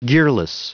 Prononciation du mot gearless en anglais (fichier audio)
Prononciation du mot : gearless